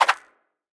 Throw Cog.wav